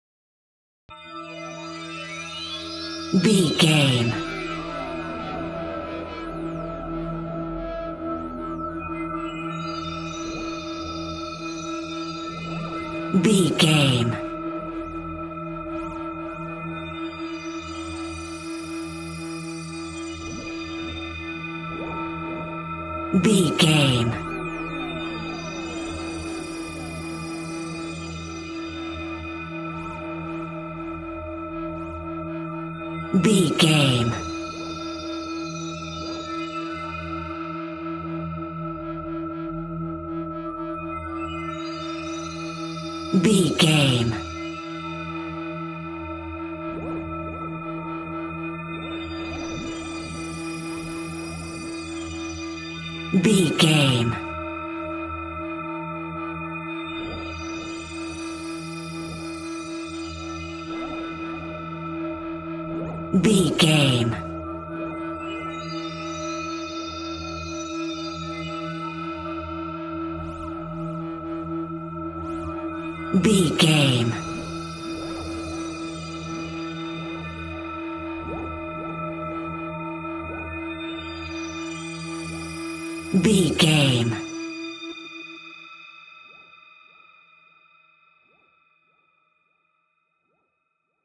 Atonal
scary
tension
ominous
dark
suspense
eerie
synthesizer
horror
Synth Pads
atmospheres